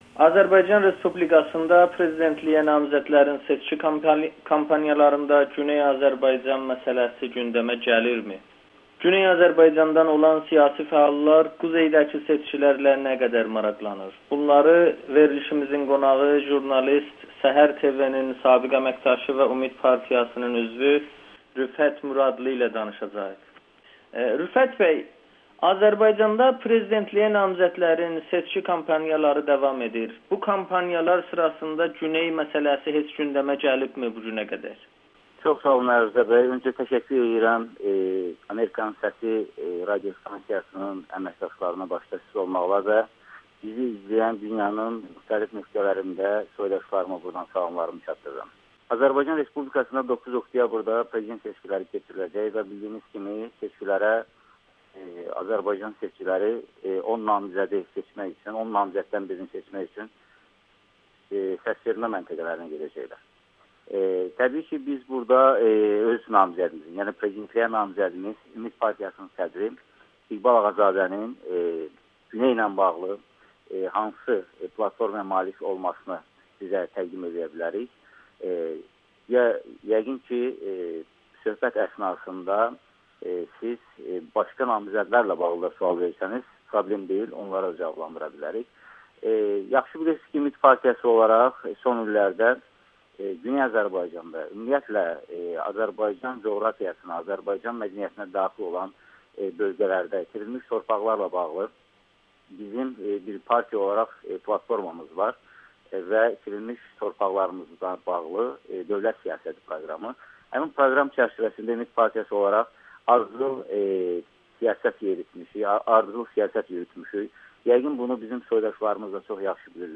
Amerikanın Səsinə müsahibədə prezidentliyə namizədlərin seçki kampaniyalarında Güney məsələsinin gündəmə çıxarılmasını müzakirə edib.